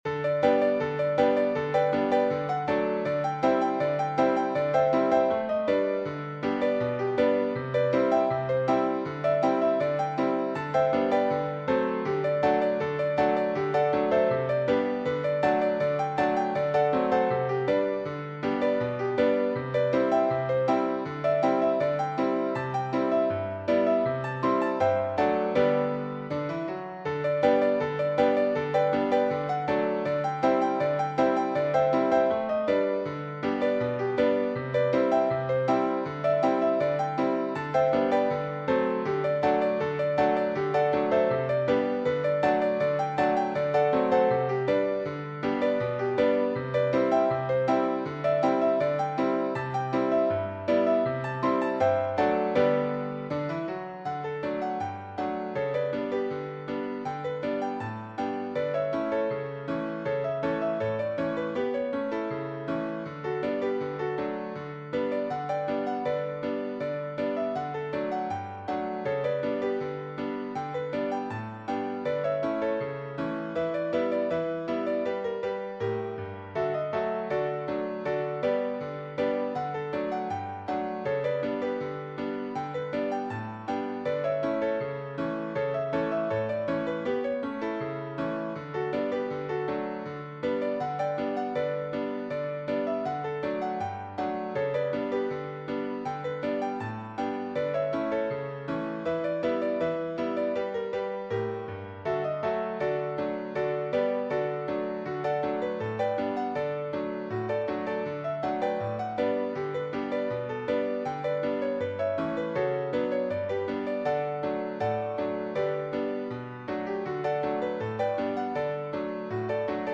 Piano only
Classic ragtime
Cheerful